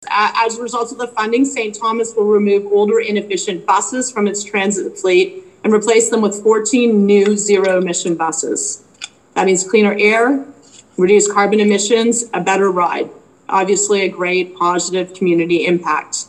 This morning, via teleconference, Catherine McKenna, federal Minister of Infrastructure and Communities, announced federal dollars for the transit upgrade were on the way.